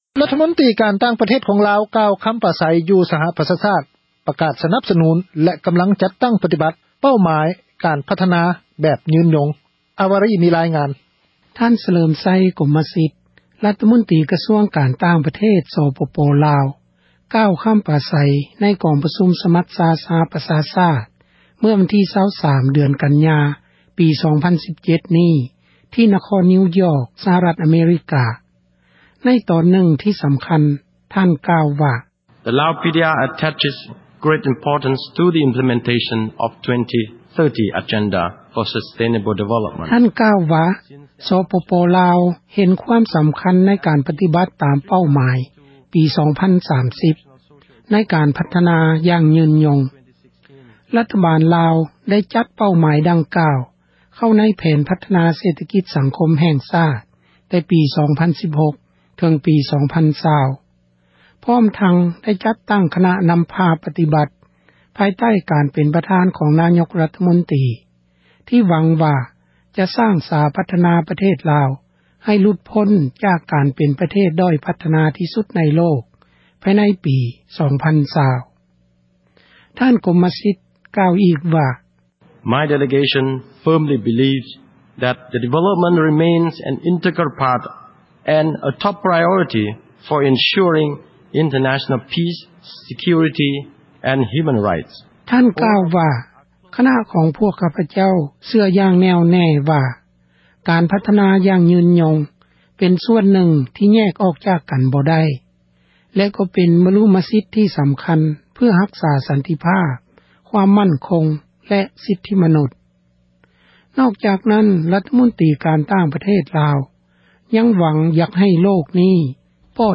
F-Laos-FM ທ່ານ ສະເຫລີມໄຊ ກົມມະສິດ ຣັຖມົນຕຣີ ກະຊວງການຕ່າງປະເທດ ສປປລາວ ກ່າວຄຳປາໃສ ໃນກອງປະຊຸມ ສະມັດຊາ ສະຫະປະຊາຊາດ ເມື່ອວັນທີ່ 23 ເດືອນກັນຍາ ປີ 2017